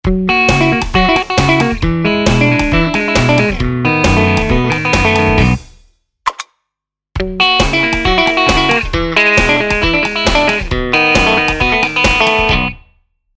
Ibanez RG Prestige 2027X
Sennheiser E-906
beigebuglowcut.mp3